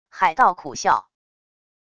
海盗苦笑wav音频